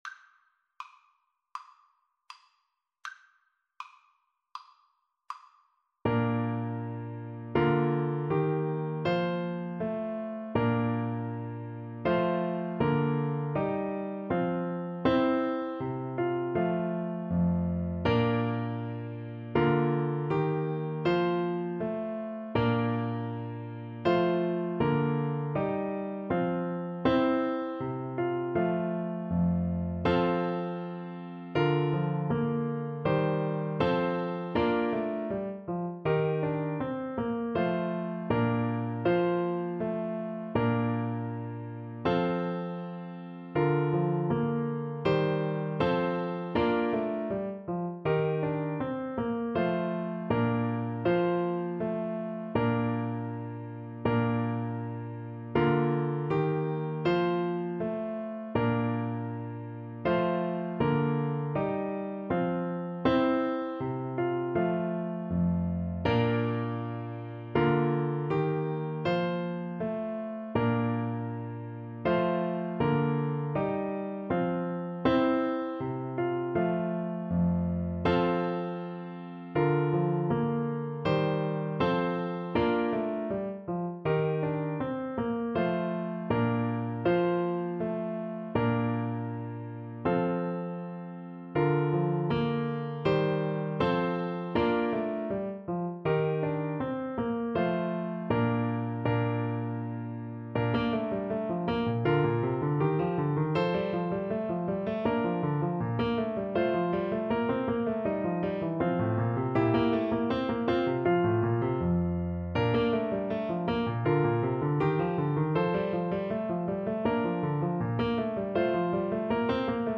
Classical
4/4 (View more 4/4 Music)
Allegretto =80